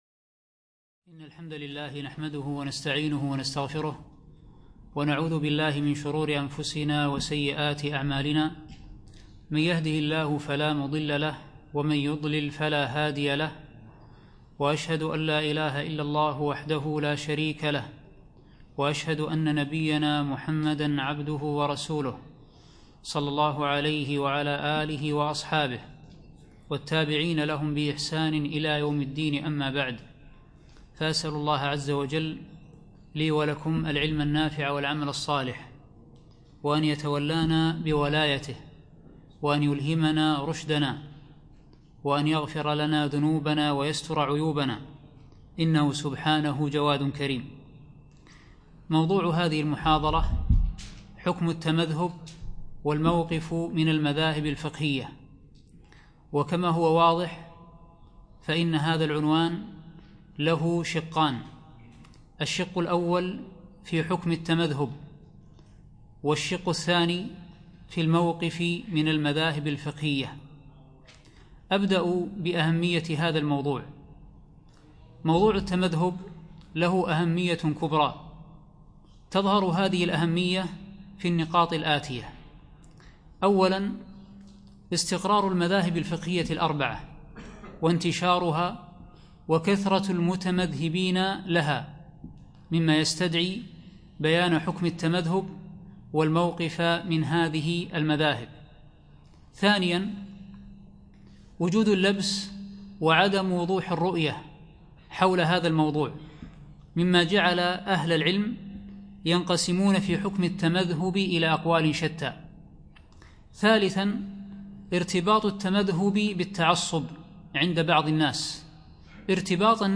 يوم الإربعاء 9 جمادى ثاني 1438 الموافق 8 3 2017 في مسجد مضحي الكليب العارضية